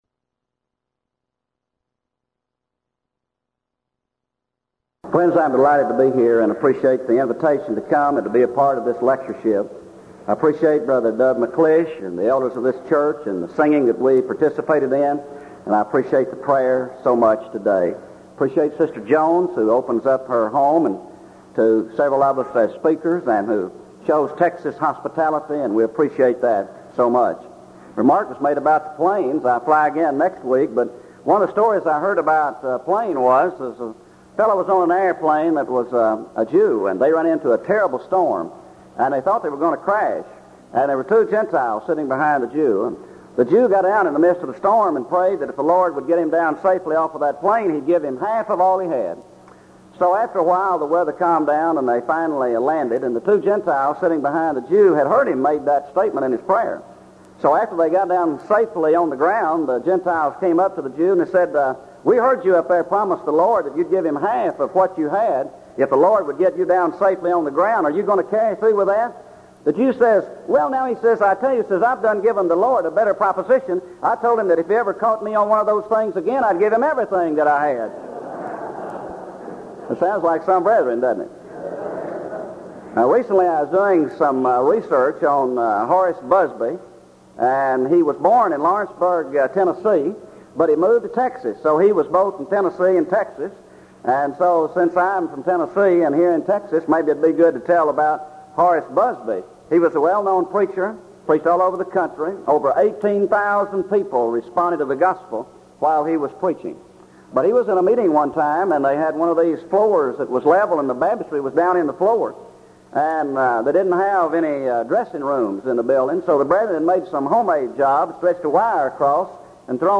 Event: 1985 Denton Lectures
lecture